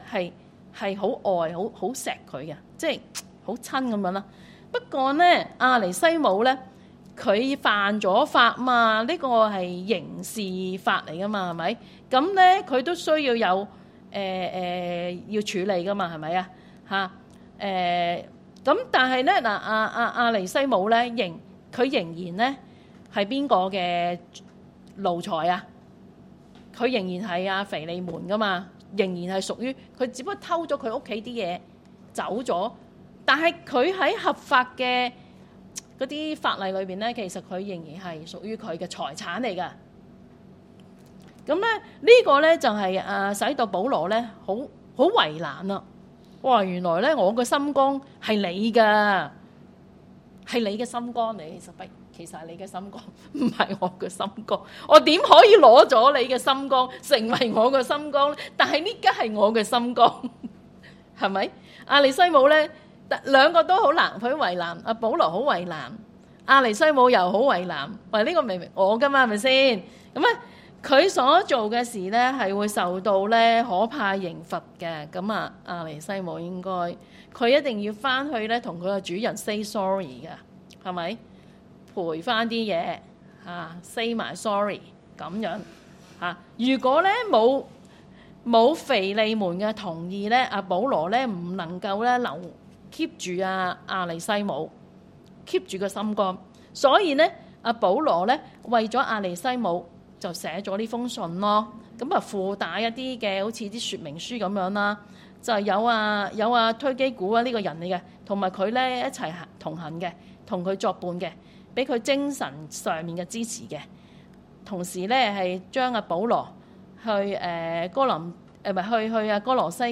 基督教香港信義會灣仔堂 - 講道重溫